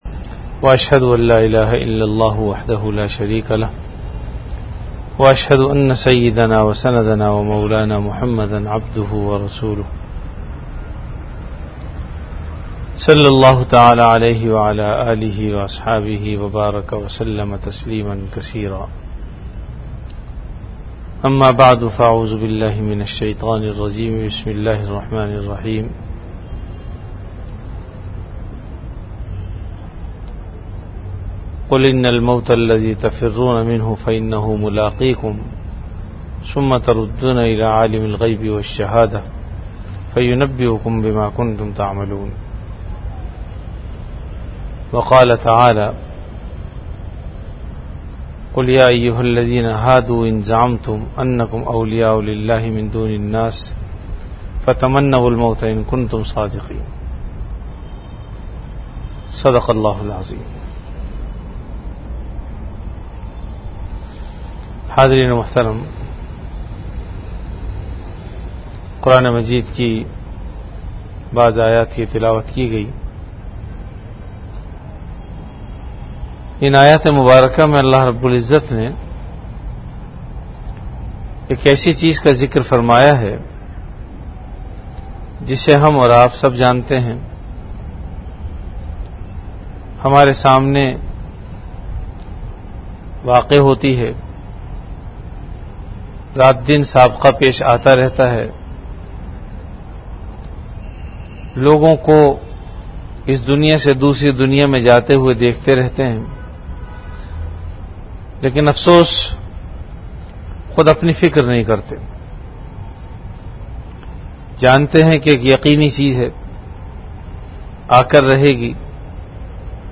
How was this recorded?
Delivered at Jamia Masjid Bait-ul-Mukkaram, Karachi. Event / Time After Isha Prayer